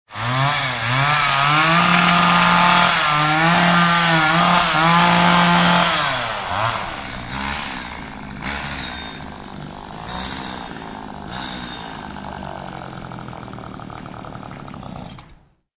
Звуки бензопилы
Звук бензопилы разрезающей дерево